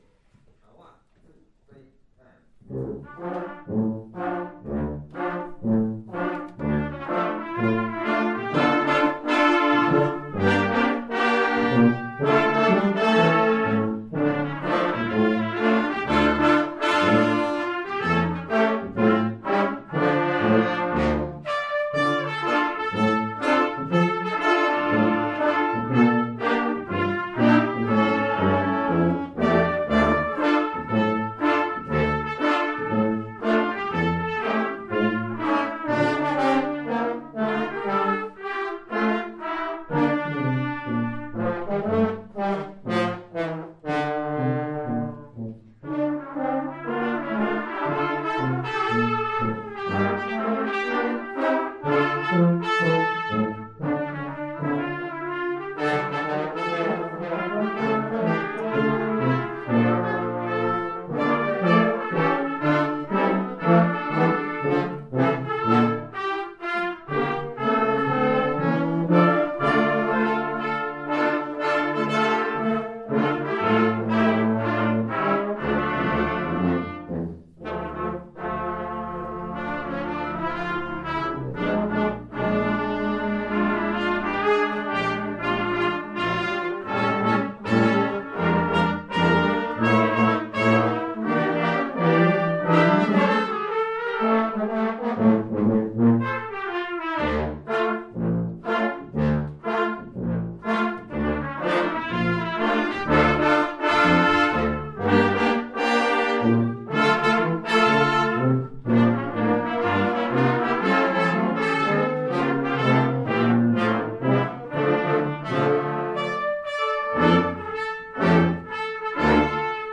Brass ensemble Joshua